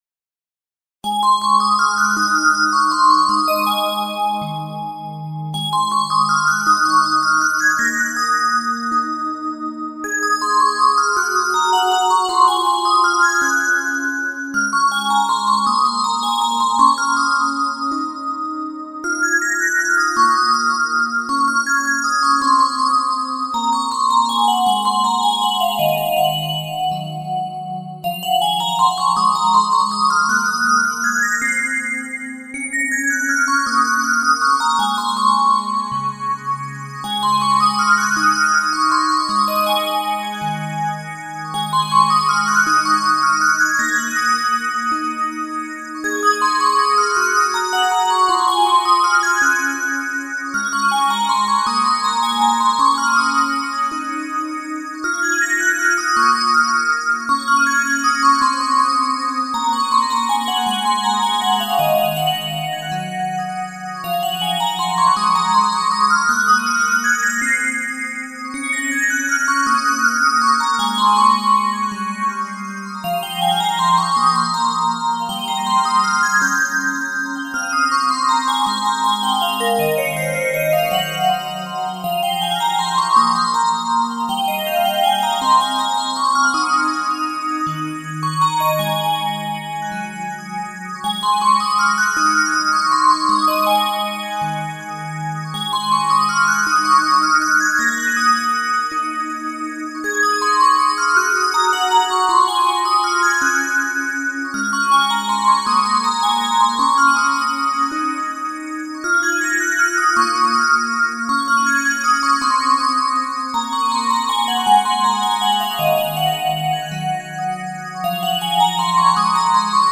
BGM
ロング明るい穏やか